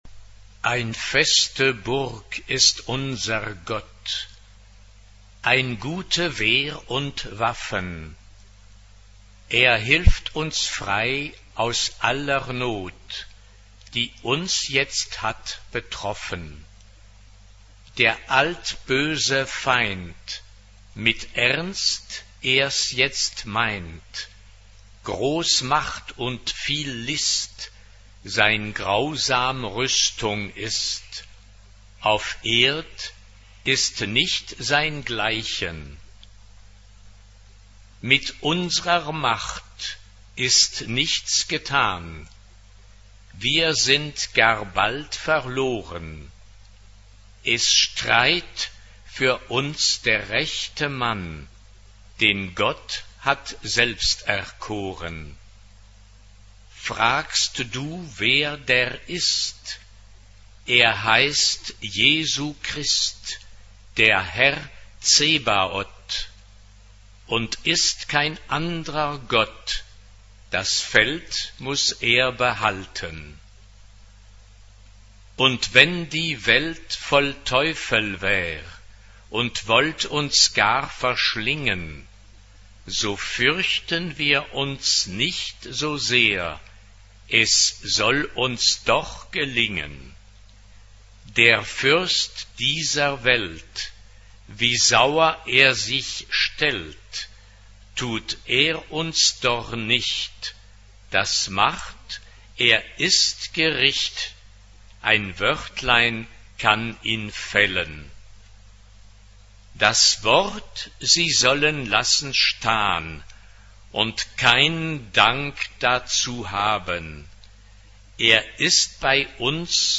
Genre-Style-Form: Sacred ; Motet ; Renaissance Type of Choir: SATB (4 mixed voices )
Tonality: E flat major